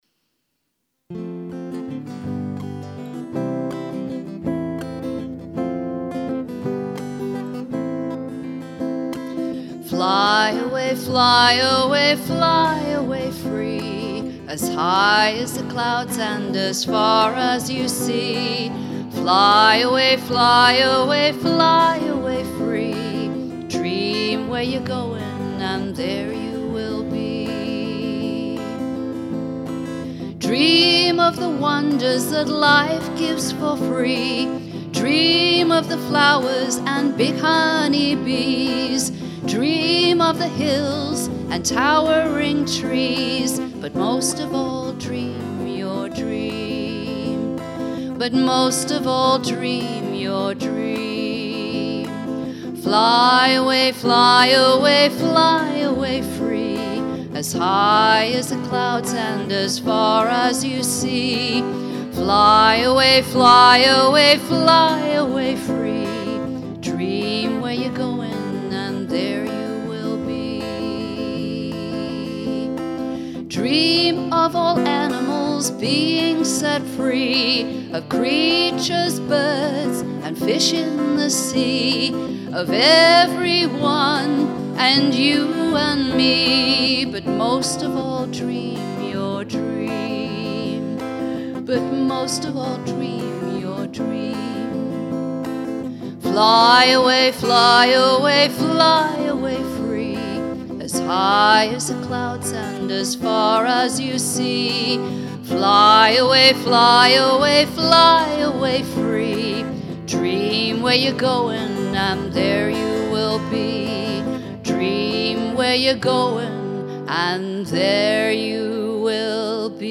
vocals and guitar